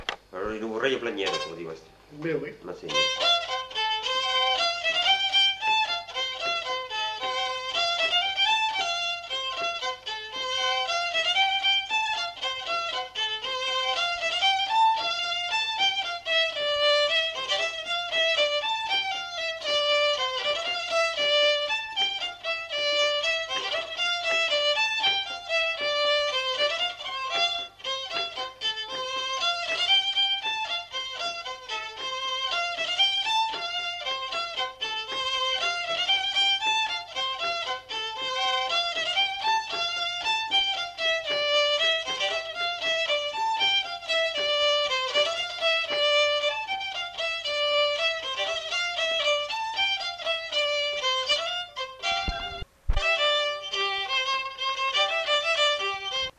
Genre : morceau instrumental
Instrument de musique : violon
Danse : bourrée
Ecouter-voir : archives sonores en ligne